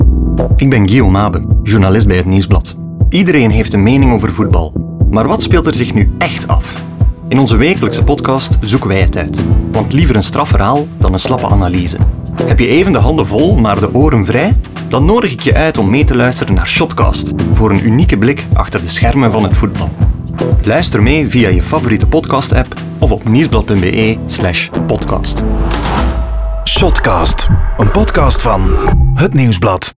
Radio Production Company: Raygun
HetNieuwsblad_NL30s_Sjotkast_Radio.wav